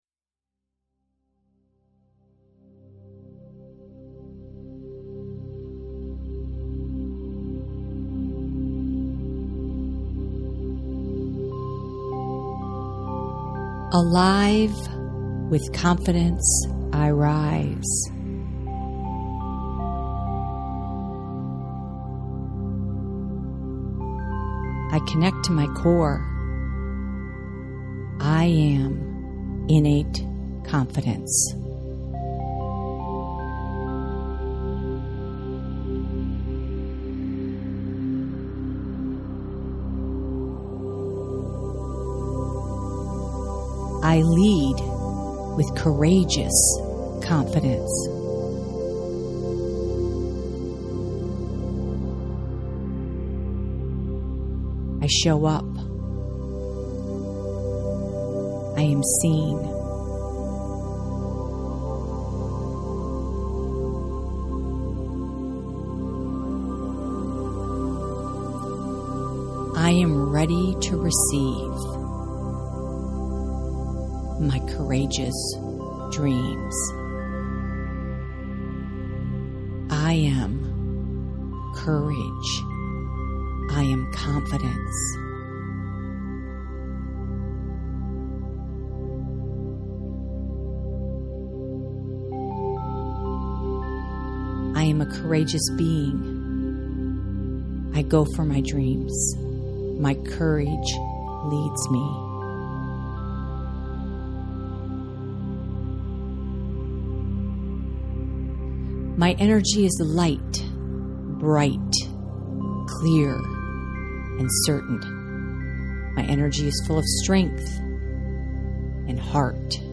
Courageous+Confidence+Mantra+Meditation.mp3